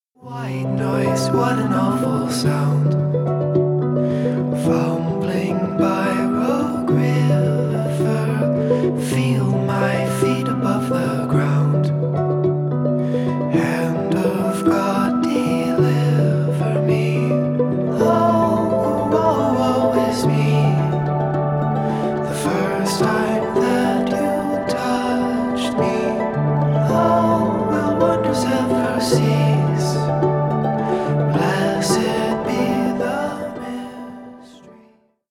• Категория: Easy listening
Инди
Спокойные